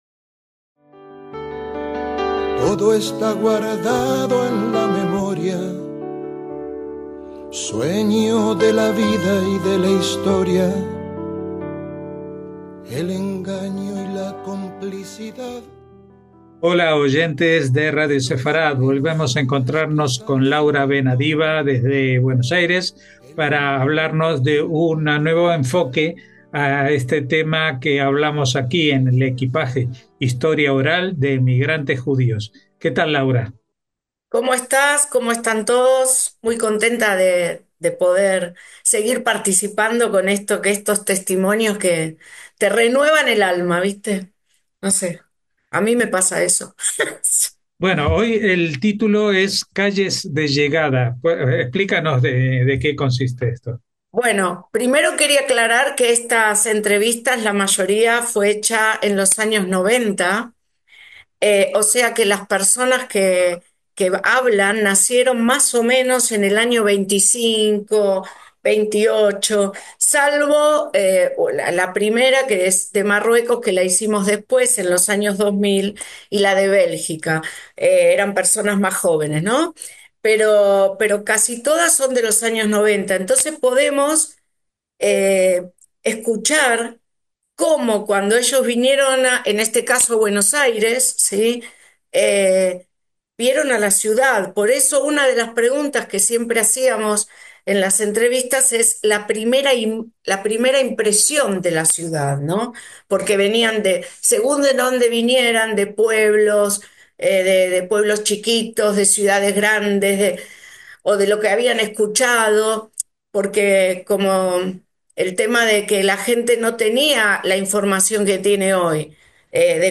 HISTORIA ORAL DE EMIGRANTES JUDÍOS